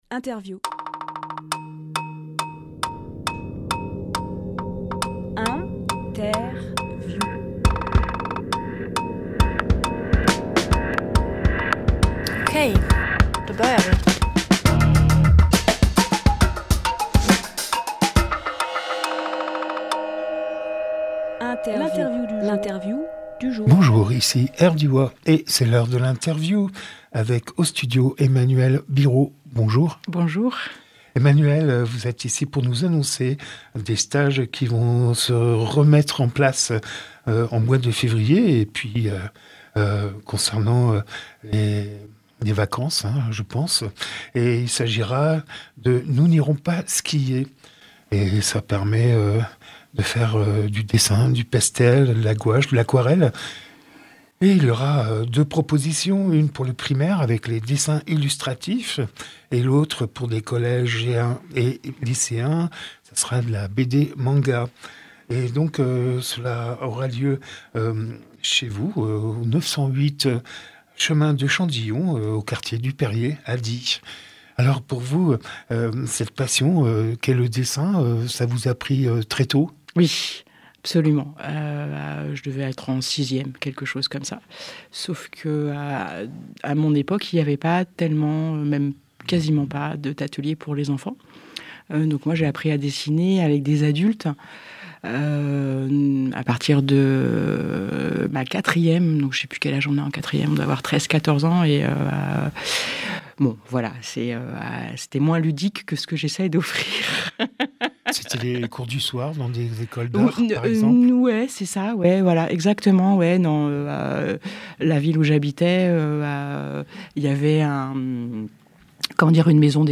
Emission - Interview Nous n’irons pas skier Publié le 20 janvier 2026 Partager sur…
lieu : Studio RDWA